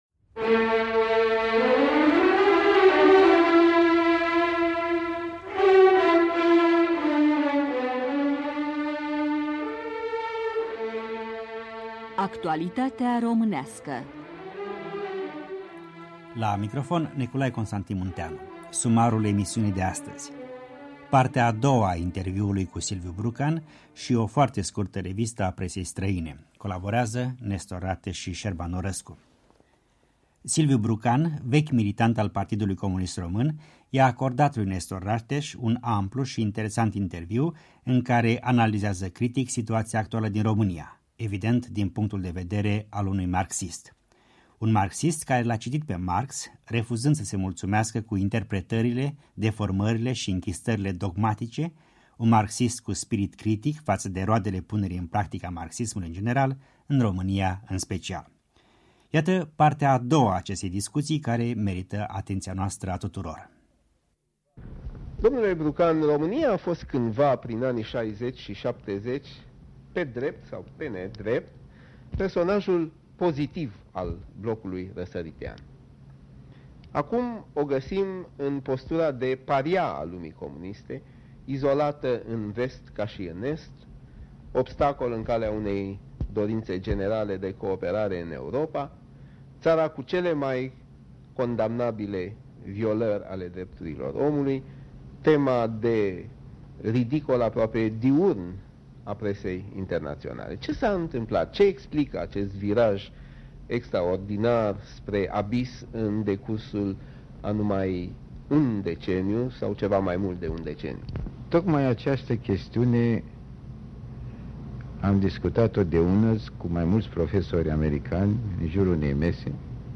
Actualitatea românească: Un interviu cu Silviu Brucan (II)